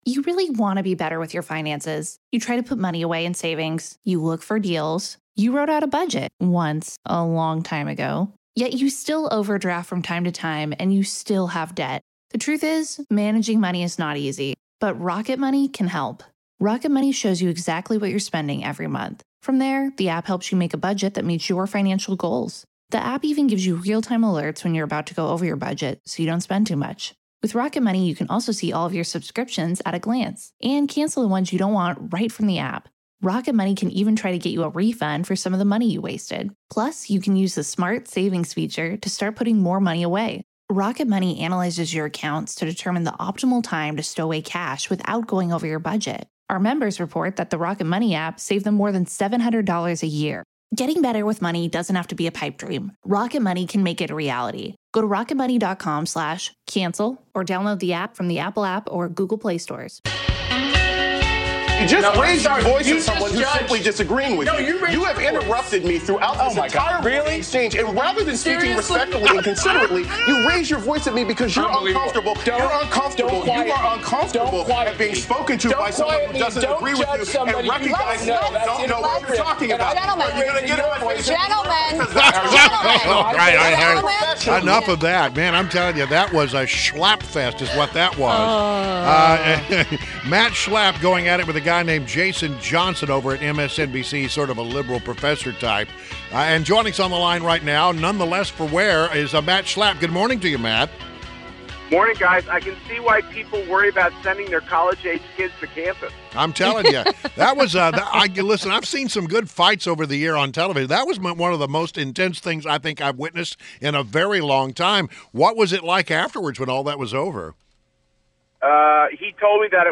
INTERVIEW – MATT SCHLAPP — Chairman of the American Conservative Union